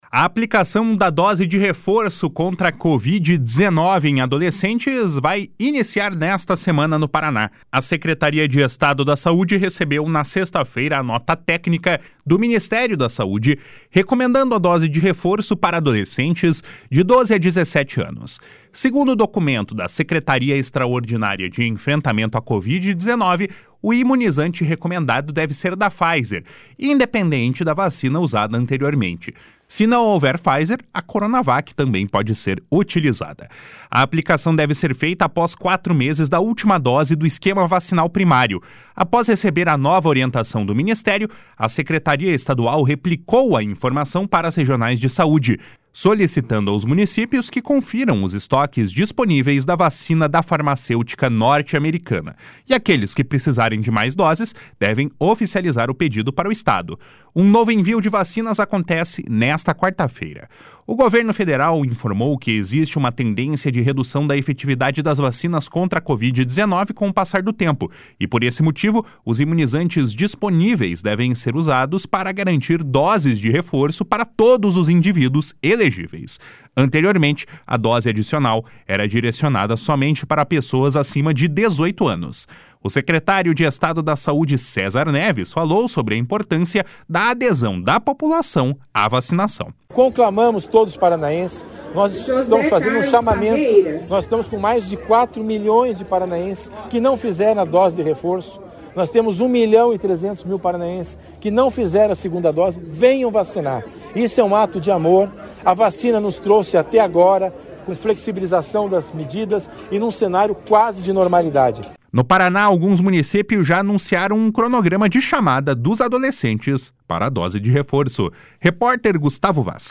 O secretário de Estado da Saúde, César Neves, falou sobre a importância da adesão da população a vacinação.